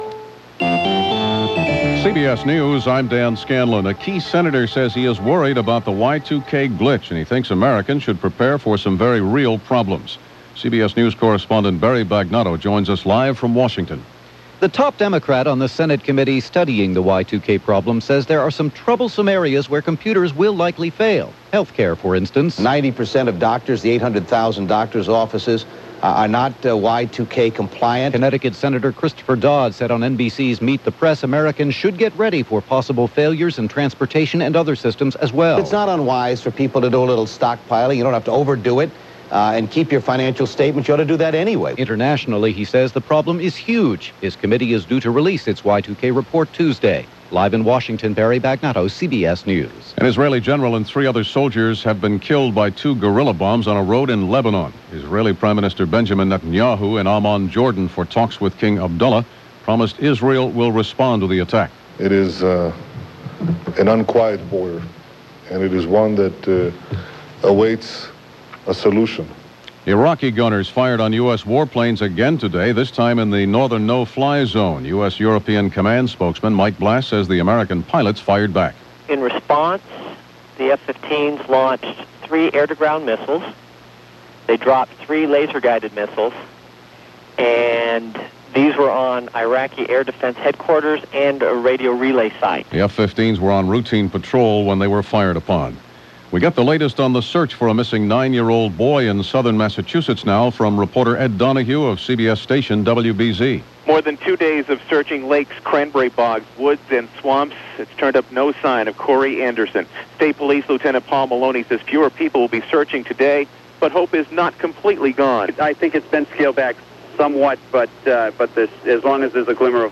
” description_text=”February 28, 1999 – CBS News On The Hour
And that’s just a little of what happened, this February 28, 1999 as reported by CBS Radio’s News On The Hour.